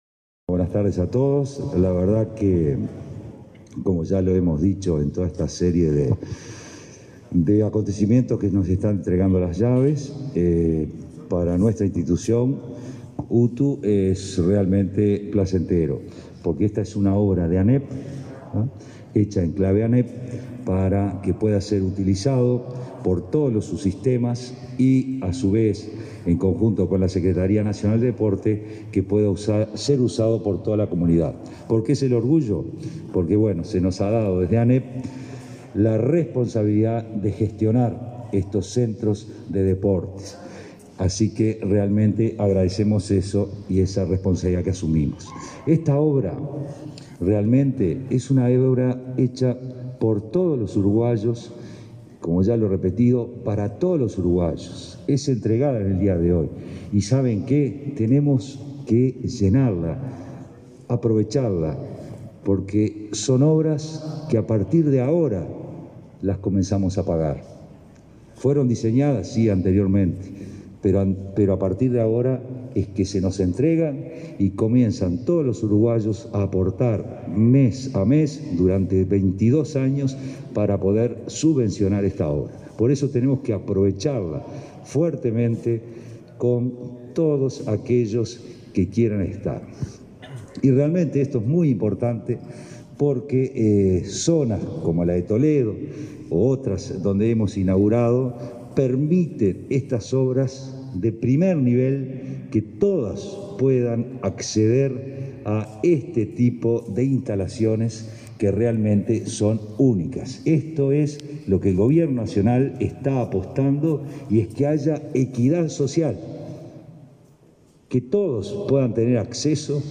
Conferencia de prensa de autoridades de la educación por entrega de instituto de UTU en Montevideo y polideportivo en Canelones
El secretario de Deporte, Sebastián Bauzá; el presidente del Codicen, Robert Silva, y el director de UTU, Juan Pereyra, participaron, este 10 de
conferencia.mp3